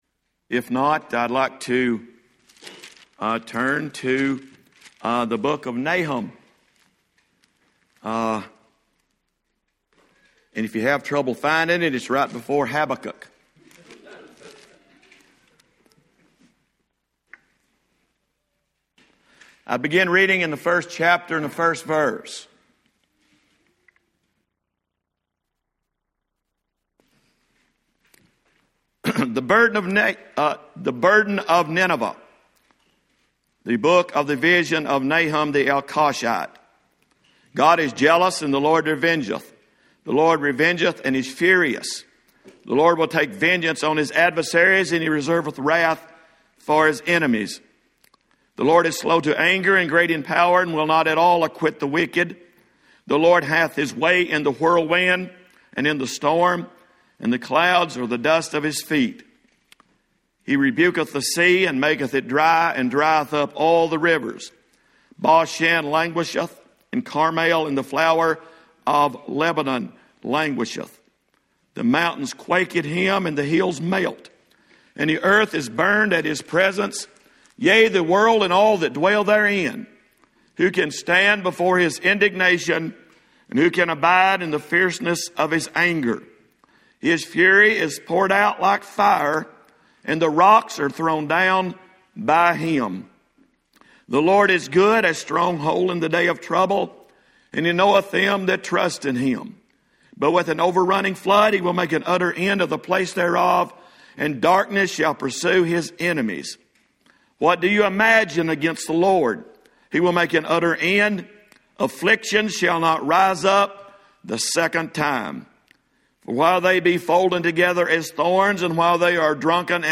Friday Evening revival service from 2019-07-26 at Old Union Missionary Baptist Church in Bowling Green, KY.